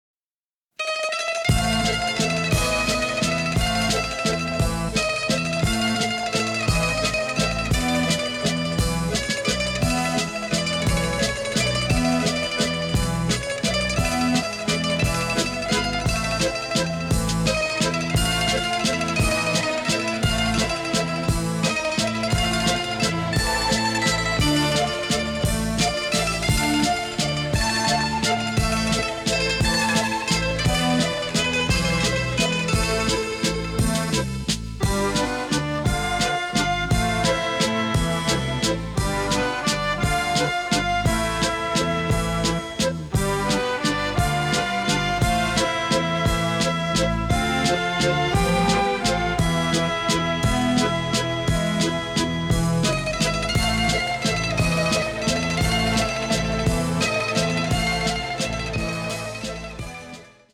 in stereo and mint condition